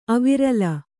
♪ avirala